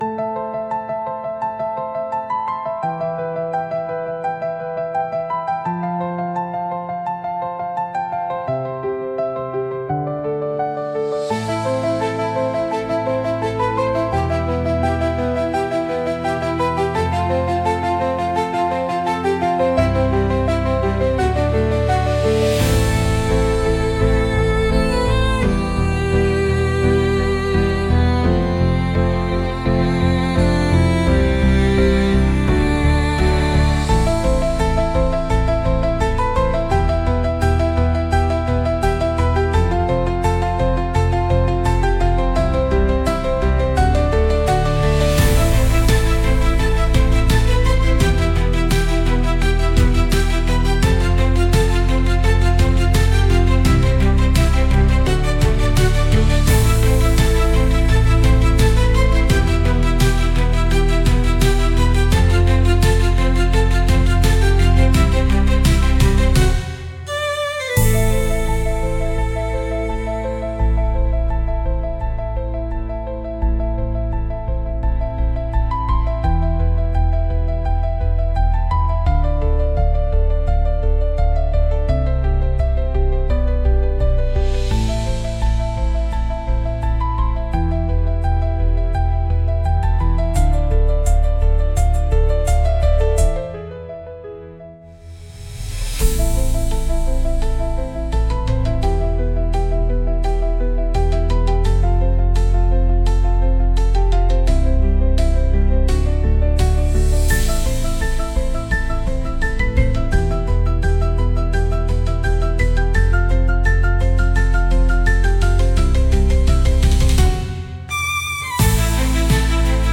Genre: Melancholic Mood: Piano Editor's Choice